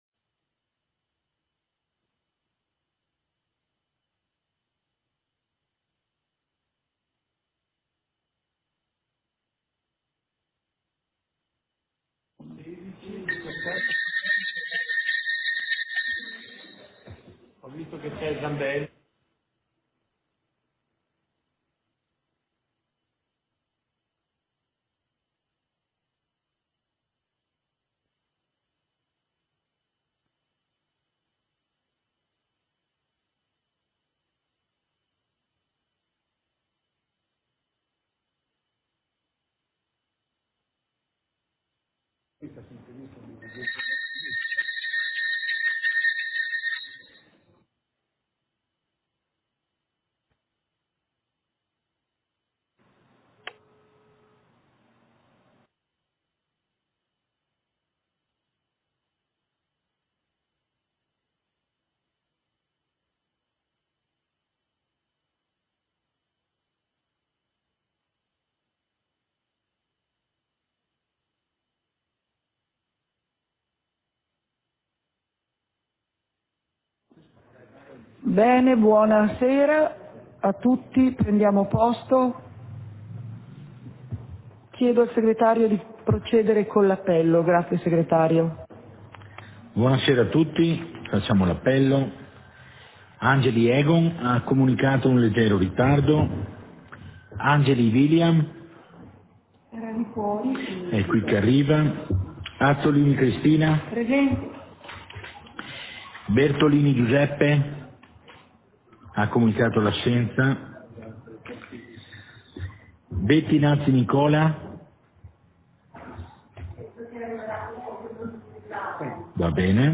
Seduta del consiglio comunale - 24.05.2022